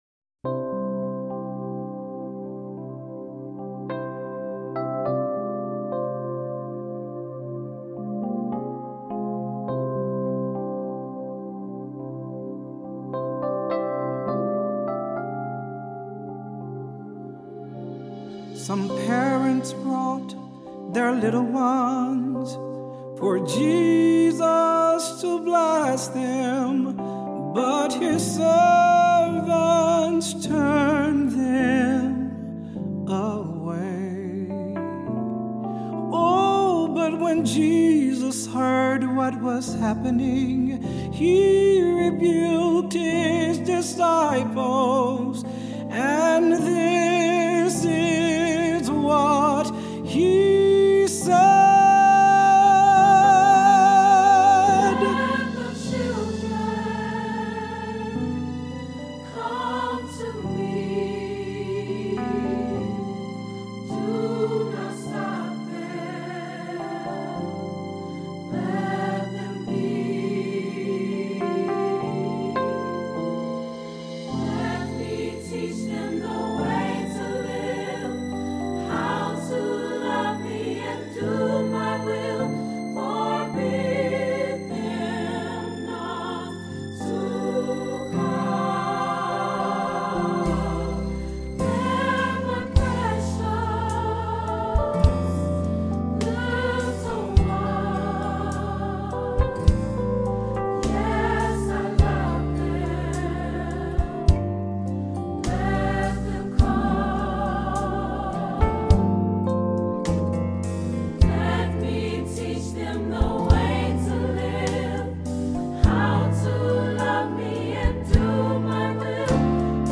Voicing: Three-part choir; Cantor; Assembly